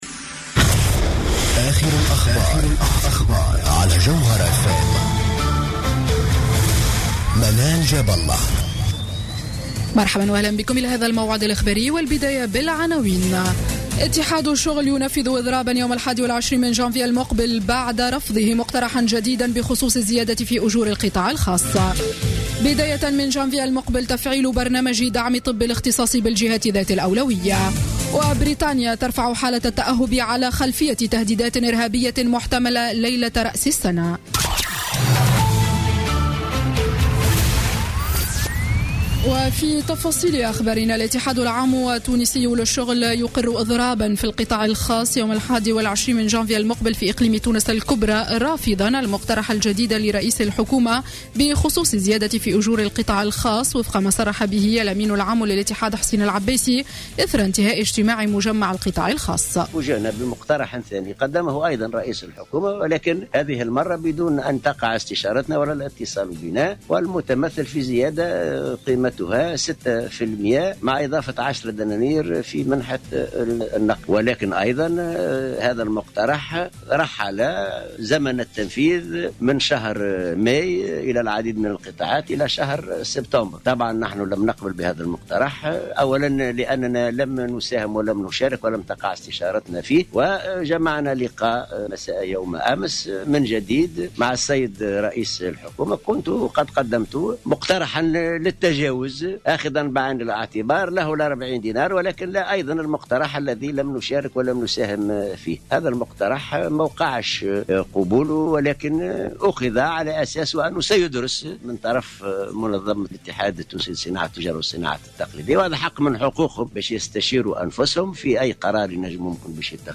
نشرة أخبار السابعة مساء ليوم الاثنين 28 ديسمبر 2015